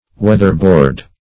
Weather-board \Weath"er-board`\, v. t. (Arch.)
Weatherboard \Weath"er*board`\, n.